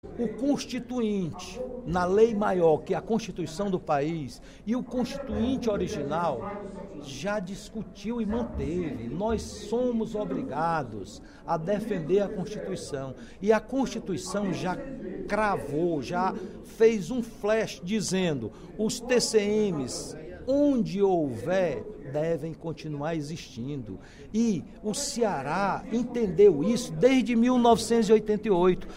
O deputado Roberto Mesquita (PSD) criticou, no primeiro expediente da sessão plenária desta quarta-feira (14/06), a tramitação da PEC 07/2017, que prevê a extinção do Tribunal de Contas dos Municípios.